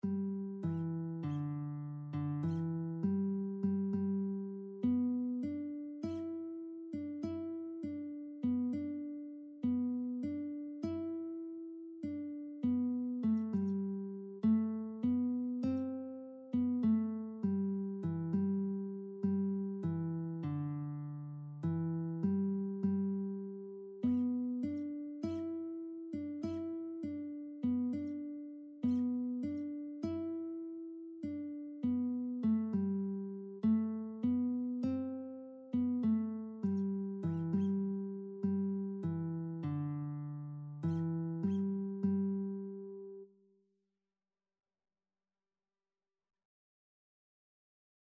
4/4 (View more 4/4 Music)
Scottish